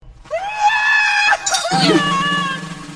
Sound Effects
King Pig Crying